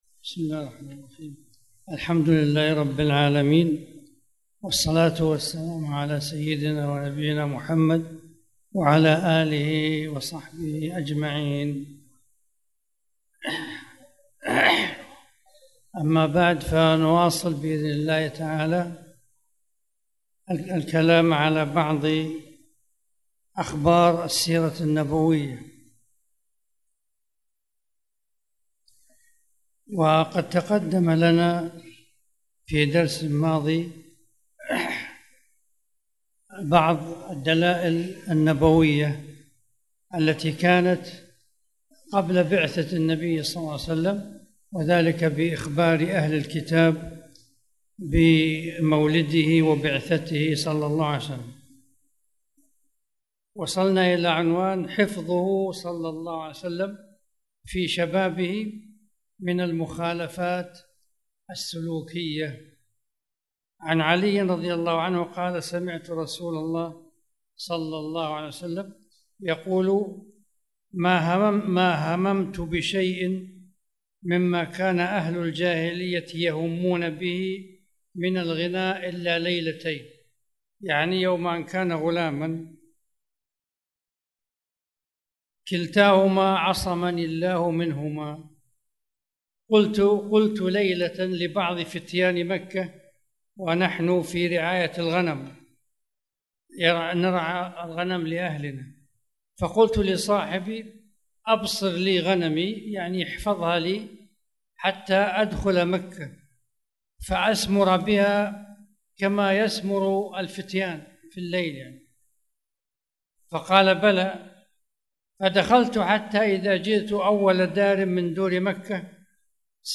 تاريخ النشر ١٦ ربيع الثاني ١٤٣٨ هـ المكان: المسجد الحرام الشيخ